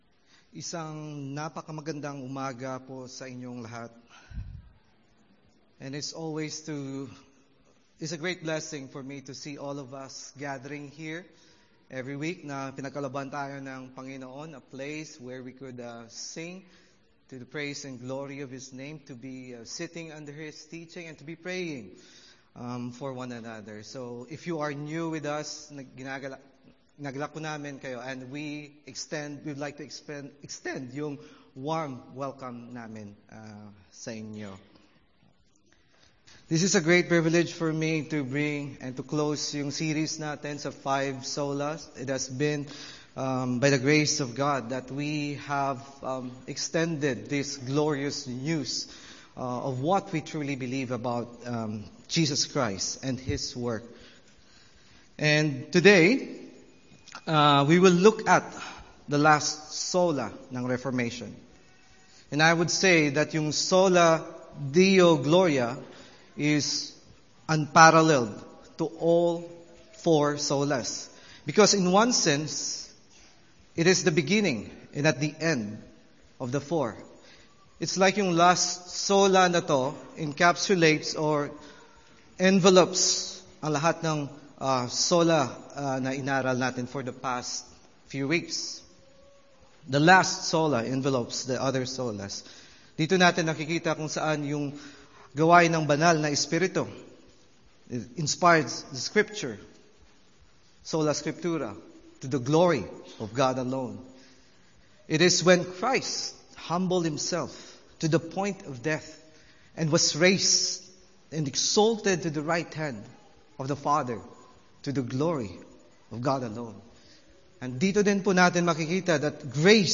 Sermon series on the five solas of the reformation